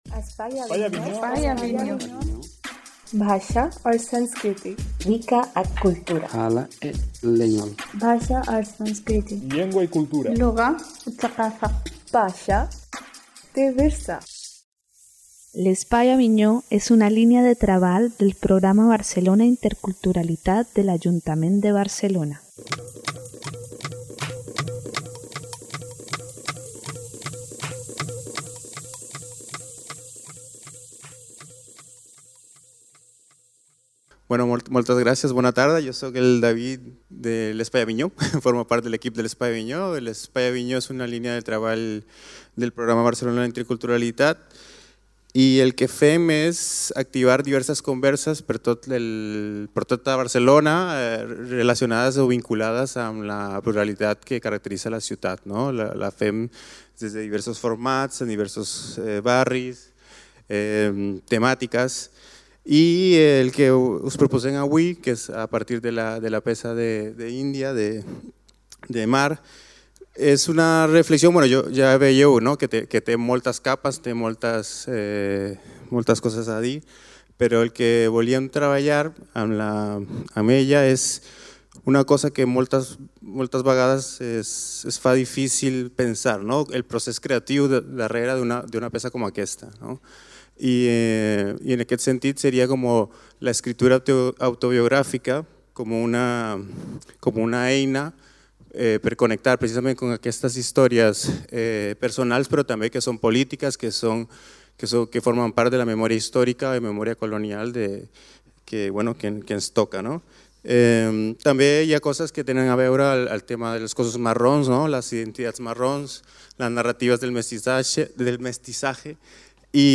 A partir d’aquesta proposta escènica, us convidem a una conversa posterior per analitzar com s’entrecreuen el racisme, el classisme i el gènere des d’una perspectiva històrica i interseccional. Què suposa un exercici d’escriptura autobiogràfica i de recuperació de les vivències dels “cossos marrons” en el marc de les jerarquies racials contemporànies?